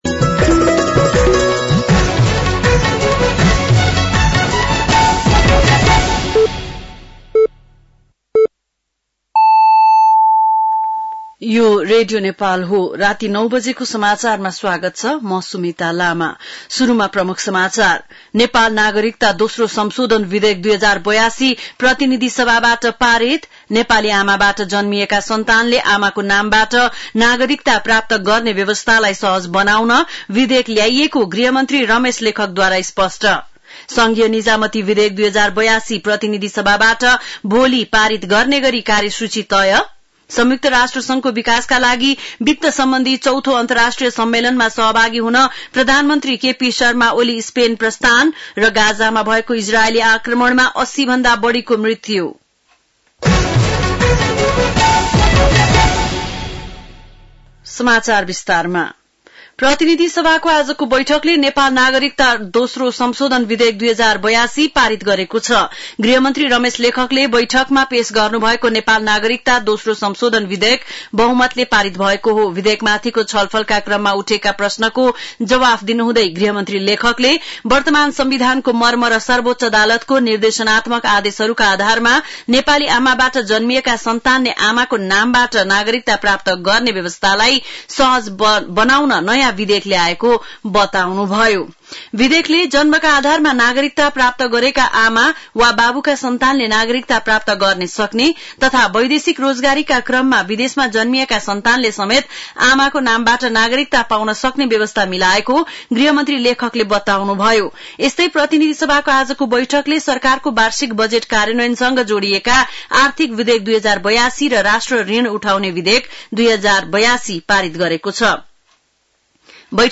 बेलुकी ९ बजेको नेपाली समाचार : १४ असार , २०८२
9-pm-nepali-news-1-3.mp3